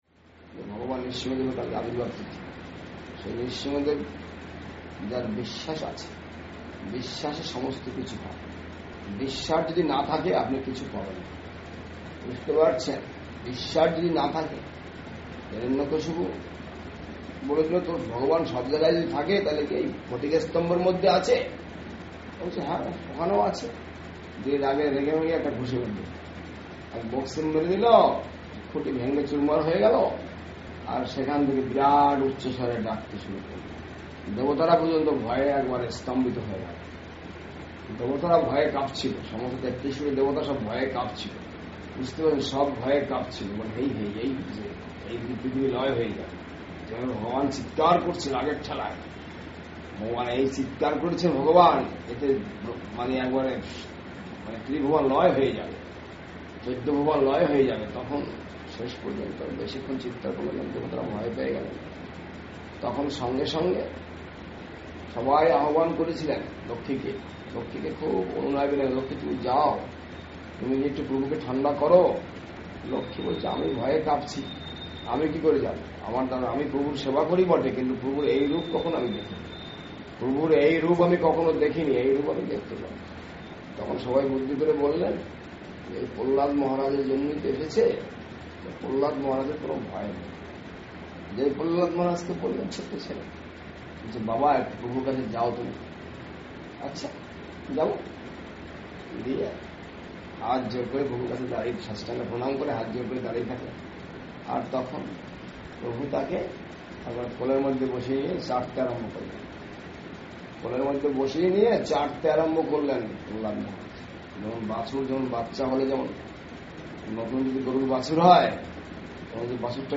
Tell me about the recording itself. Eve of Sri Nrisingha Chaturdasi, 2 May 2015, Sri Nabadwip Dham, part 3